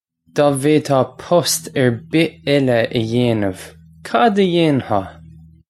Pronunciation for how to say
Daw vade-haw pust urr bih ella uh yay-nuv, kod uh yayn-haw?
This comes straight from our Bitesize Irish online course of Bitesize lessons.